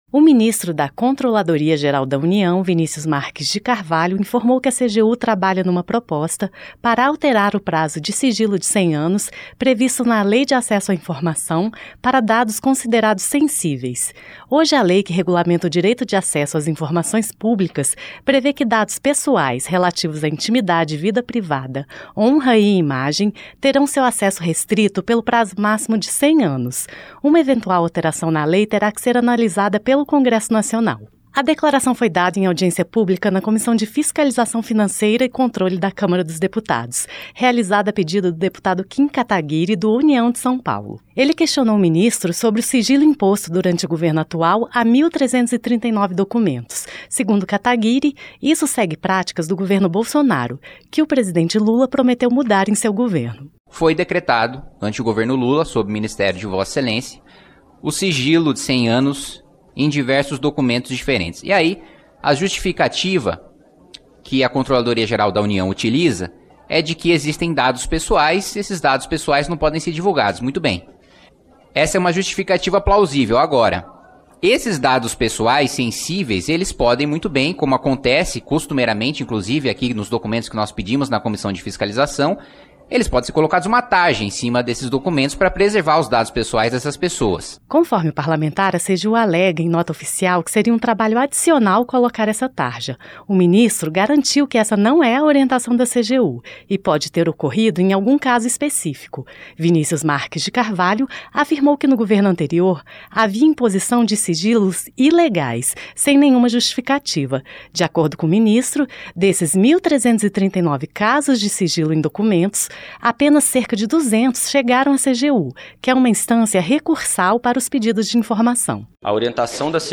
Áudio da matéria